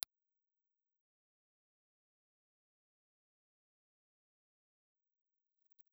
Impulse Response file of the Velocibel 36A ribbon microphone.
Velocibel_Ribbon_IR.wav